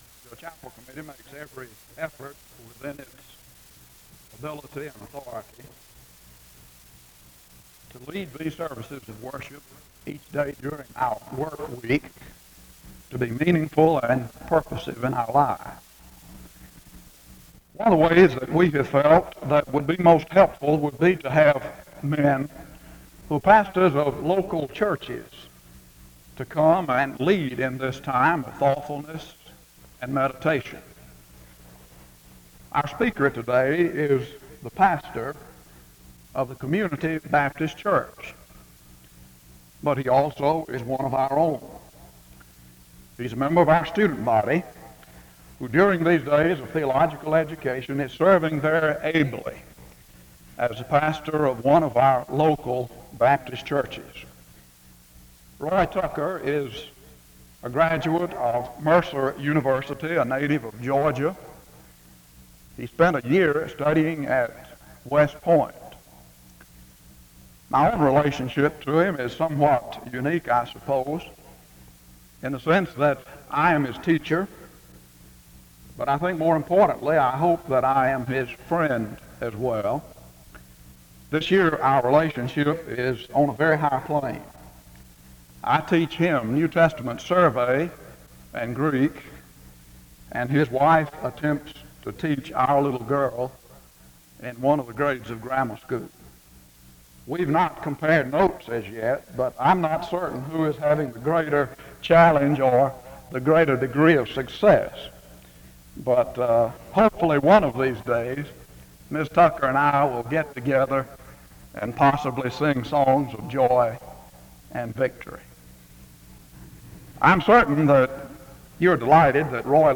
The service begins with an introduction to the speaker from 0:00-2:14.
SEBTS Chapel and Special Event Recordings SEBTS Chapel and Special Event Recordings